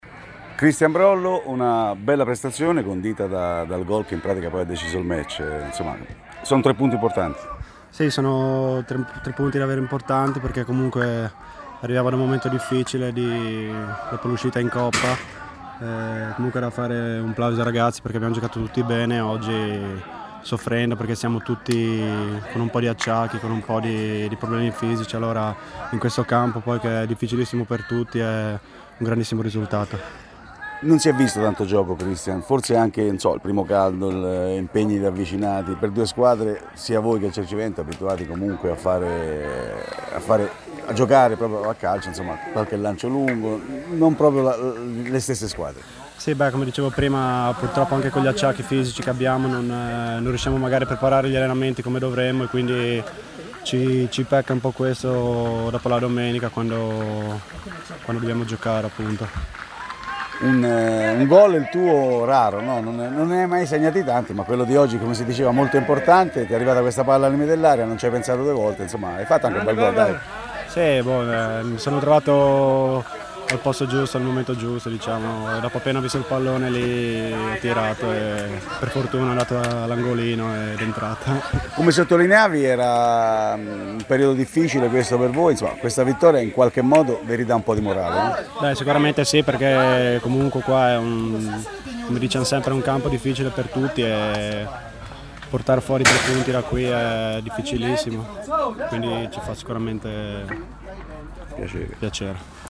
Proponiamo le audiointerviste registrate al termine dell’anticipo di Prima Categoria fra Cercivento e Real, vinta 1-0 dagli ospiti.